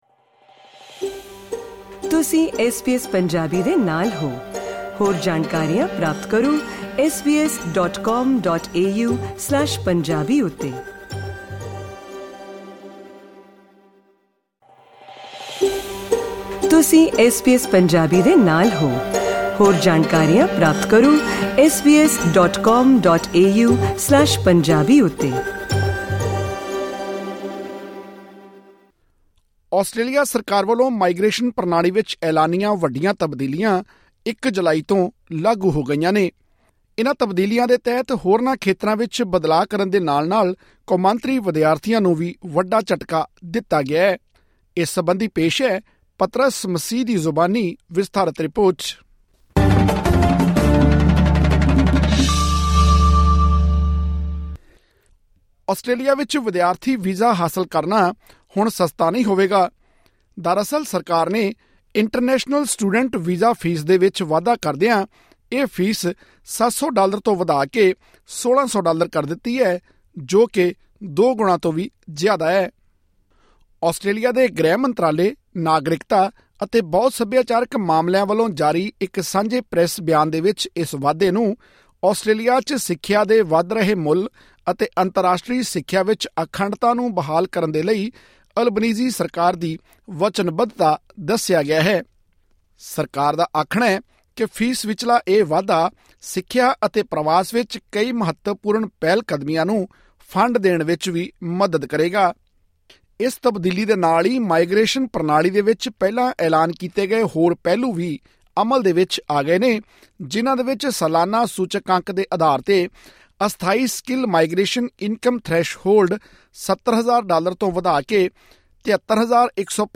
ਆਸਟ੍ਰੇਲੀਆ ਸਰਕਾਰ ਵਲੋਂ ਮਾਈਗ੍ਰੇਸ਼ਨ ਪ੍ਰਣਾਲੀ ਵਿੱਚ ਐਲਾਨੀਆਂ ਵੱਡੀਆਂ ਤਬਦੀਲੀਆਂ 1 ਜੁਲਾਈ ਤੋਂ ਲਾਗੂ ਹੋ ਗਈਆਂ ਹਨ। ਸਰਕਾਰ ਨੇ ਅੰਤਰਾਸ਼ਟਰੀ ਸਟੂਡੈਂਟ ਵੀਜ਼ਾ ਫੀਸ ਵਿੱਚ ਦੋ ਗੁਣਾਂ ਤੋਂ ਵੀ ਜ਼ਿਆਦਾ ਵਾਧਾ ਕਰਦਿਆਂ ਇਹ ਫੀਸ 710 ਡਾਲਰ ਤੋਂ ਵਧਾ ਕੇ 1600 ਡਾਲਰ ਕਰ ਦਿੱਤੀ ਹੈ। ਸਰਕਾਰ ਦਾ ਕਹਿਣਾ ਹੈ ਕਿ ਇਹ ਫੀਸ ਵਿਚਲਾ ਵਾਧਾ ਸਿੱਖਿਆ ਅਤੇ ਪ੍ਰਵਾਸ ਵਿੱਚ ਕਈ ਮਹੱਤਵਪੂਰਨ ਪਹਿਲਕਦਮੀਆਂ ਨੂੰ ਫੰਡ ਦੇਣ ਵਿੱਚ ਵੀ ਮਦਦ ਕਰੇਗਾ। ਹੋਰ ਵੇਰਵੇ ਲਈ ਸੁਣੋ ਇਹ ਰਿਪੋਰਟ....